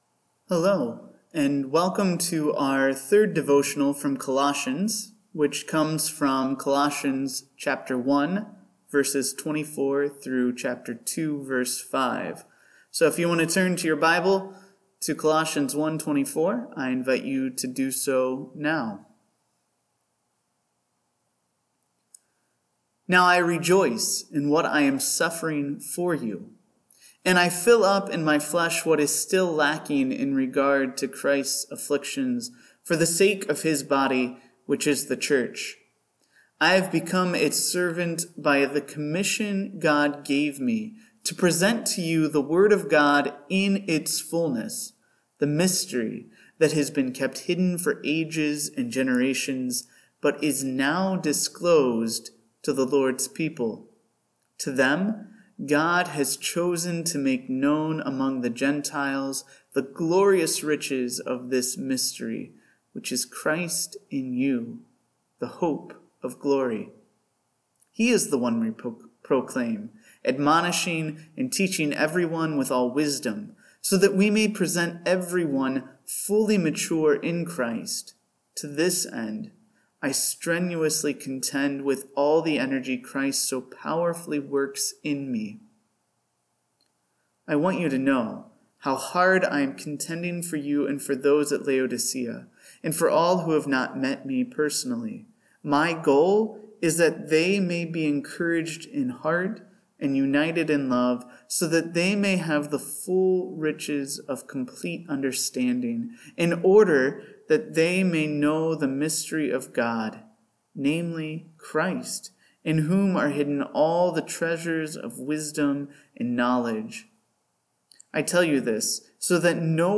All Things – Devotional